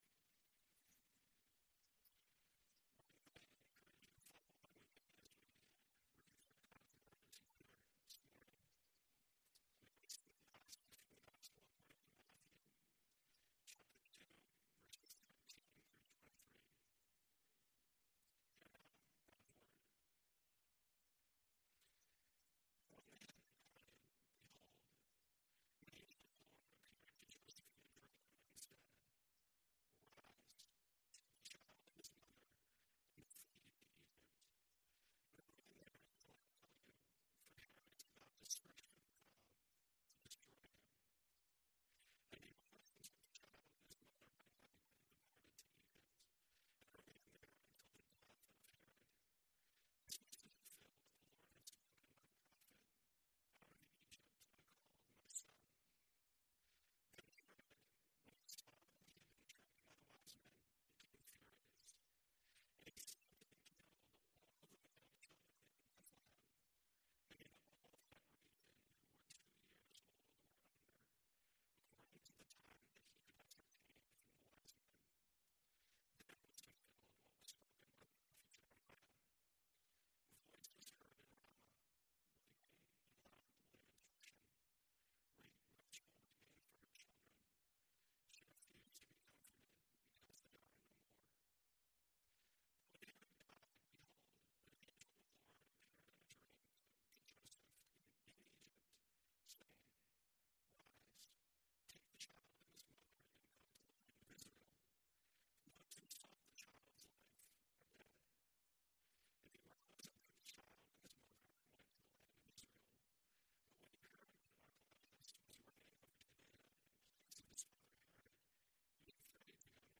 Sermon Passage: Matthew 2:13-23 Service Type: Sunday Worship